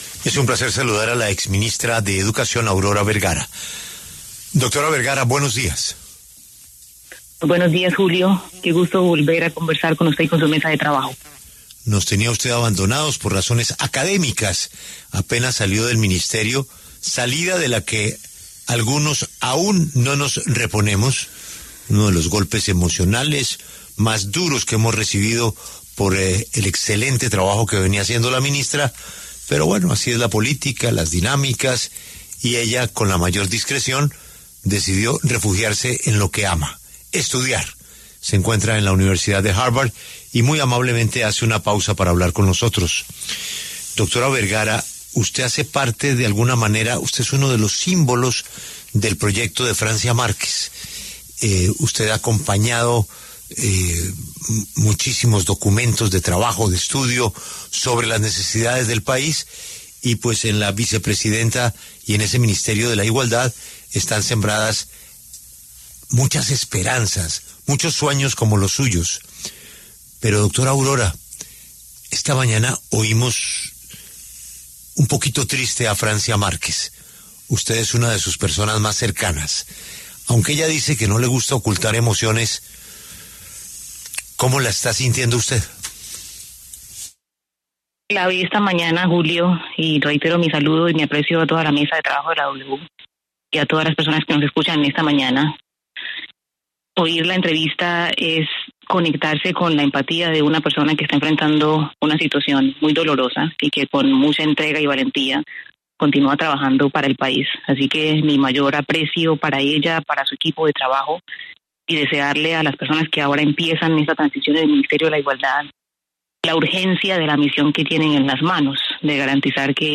La exministra de Educación, Aurora Vergara, conversó con La W sobre la salida de Francia Márquez del Ministerio de Igualdad, el funcionamiento de esta cartera y las diferencias entre el presidente Petro y la vicepresidenta.